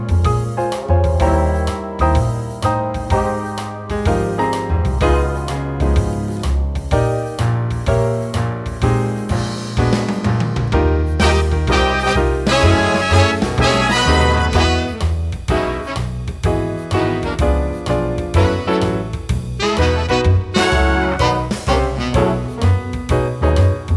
Two Semitones Down Jazz / Swing 3:00 Buy £1.50